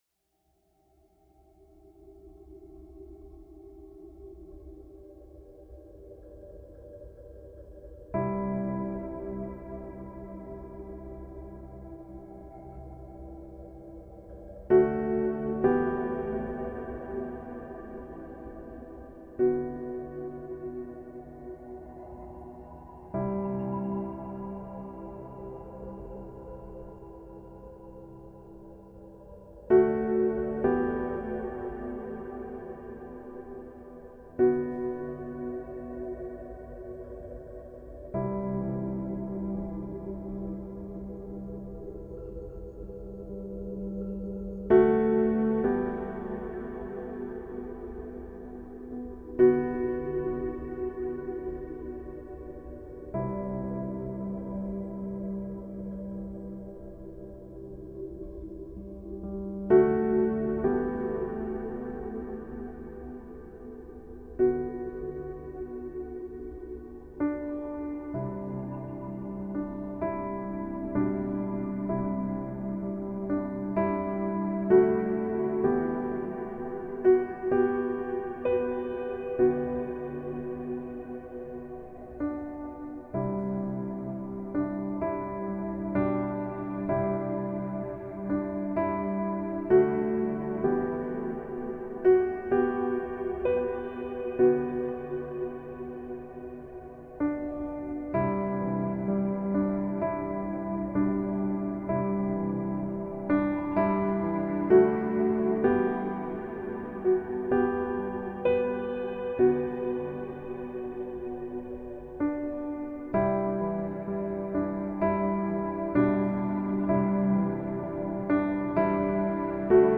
MUSICA-CRISTIANA-PIANO-INSTRUMENTA_01.mp3